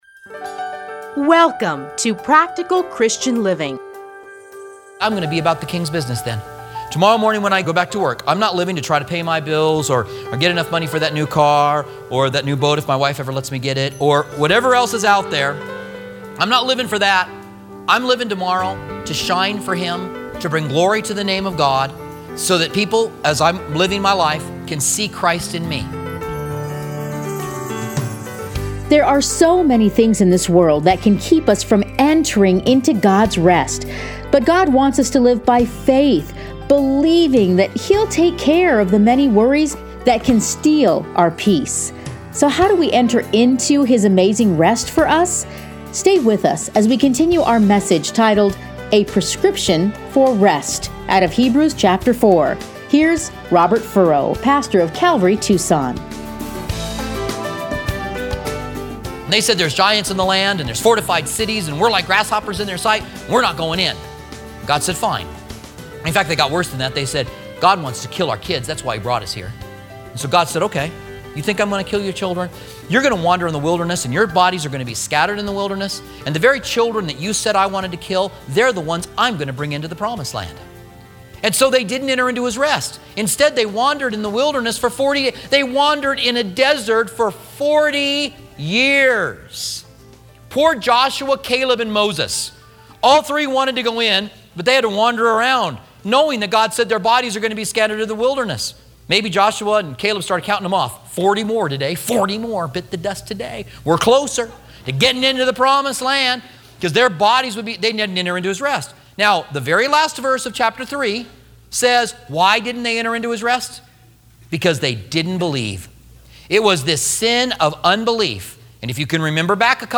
Listen here to a teaching from Hebrews.